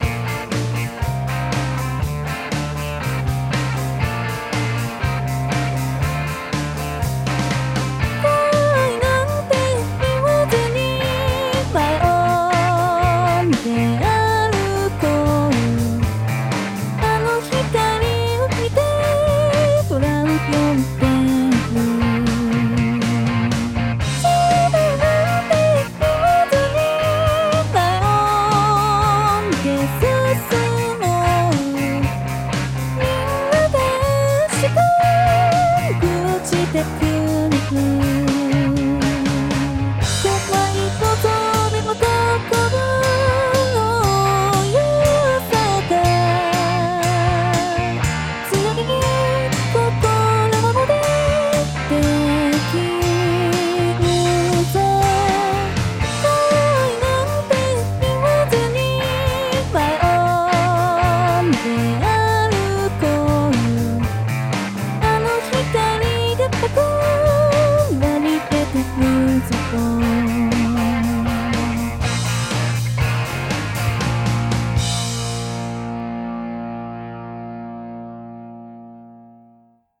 [歌]
※Band-in-a-Boxによる自動作曲